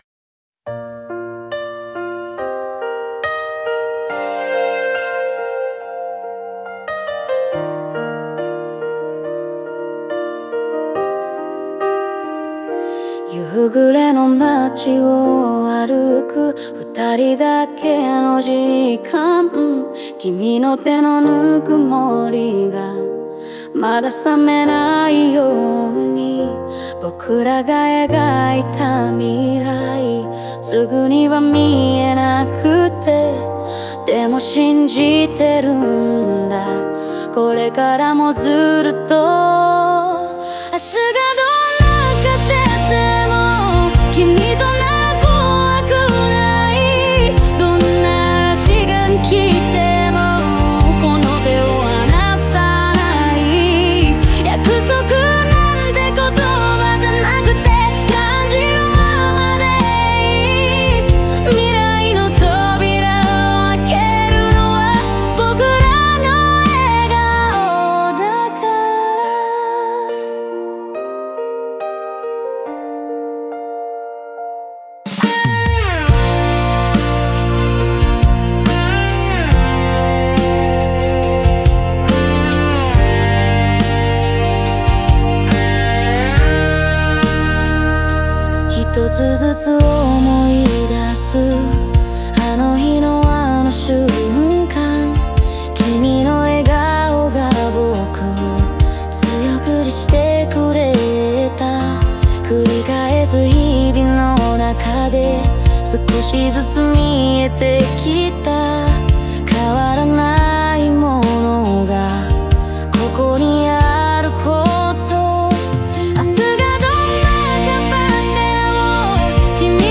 オリジナル曲♪
時が流れても変わらない絆を感じさせ、ラストに向けて感情が高まり、未来への希望を力強く歌い上げています。